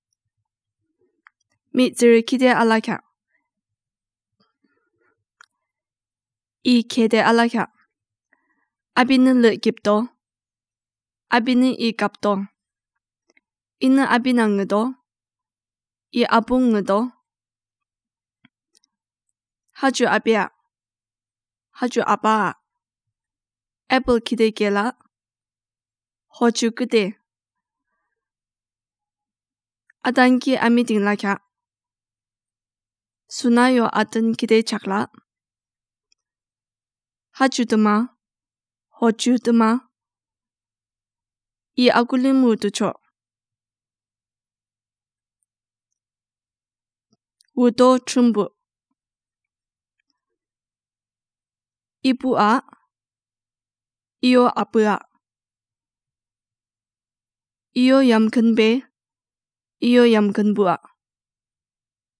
Elicitation of words for minimal pair in Yimkhiung